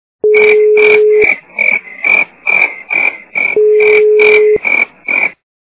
Сверчек - пение сверчка Звук Звуки Цвіркун - спів цвіркуна
» Звуки » Природа животные » Сверчек - пение сверчка
При прослушивании Сверчек - пение сверчка качество понижено и присутствуют гудки.